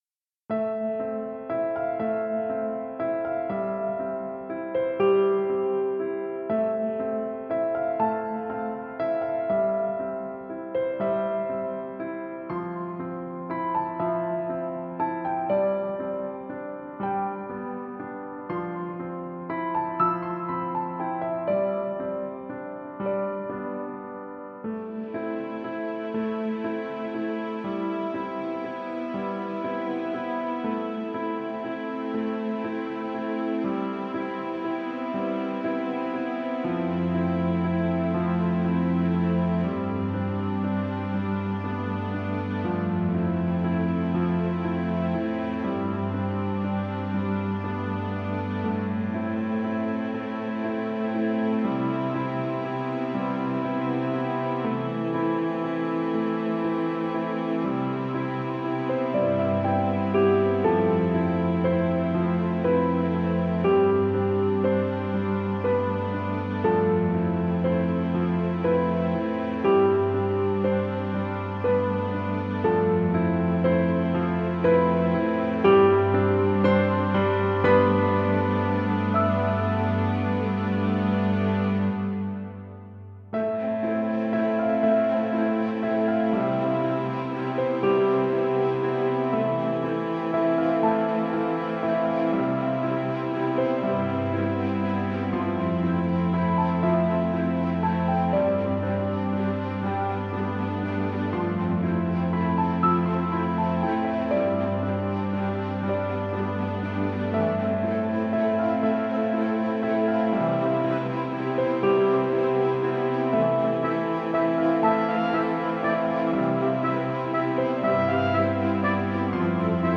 [Orchestral] Lament (MP3)
Anyway, this is a nice tune, even for me being a piano-hater :P It sets the mood so to say.
This is a very pretty piece! Has a very intimate feel.